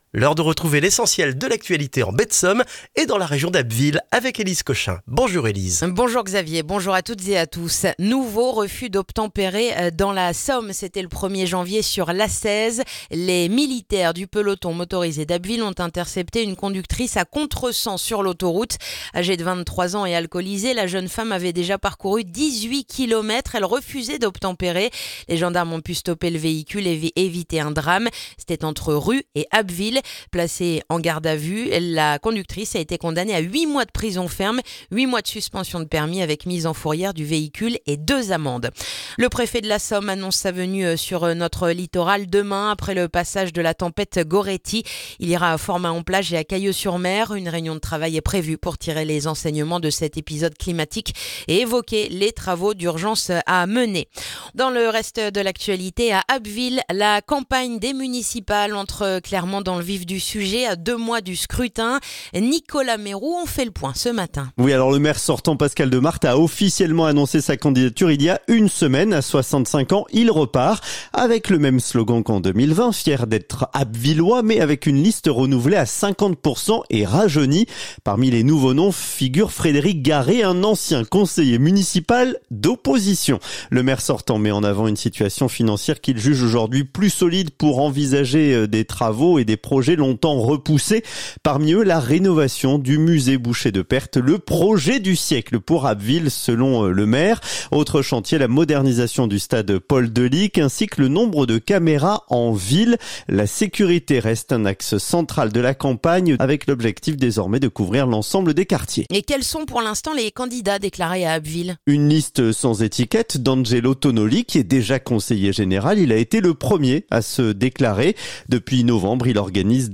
Le journal du jeudi 15 janvier en Baie de Somme et dans la région d'Abbeville